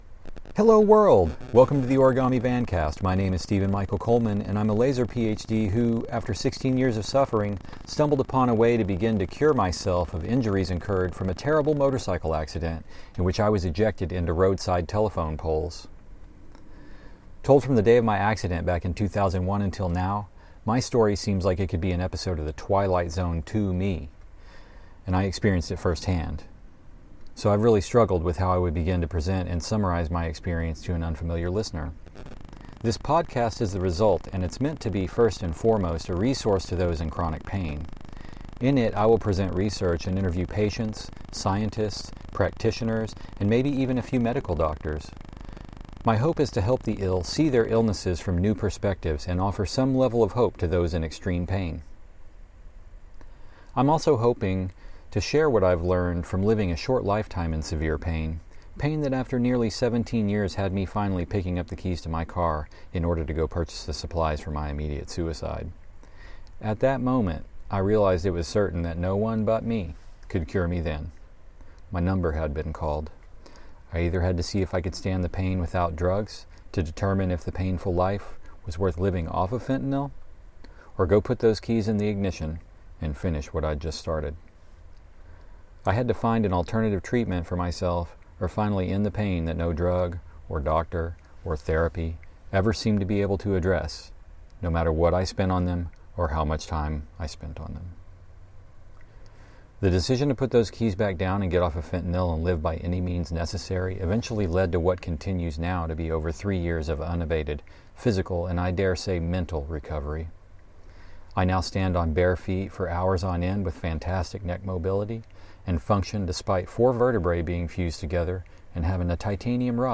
This episode was recorded in Logan County, OK in December 2020.